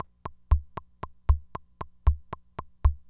Index of /90_sSampleCDs/300 Drum Machines/FG Enterprises King Beat/FG Enterprises King Beat Sample Pack_Audio Files
FG Enterprises King Beat Sample Pack_Loop1.wav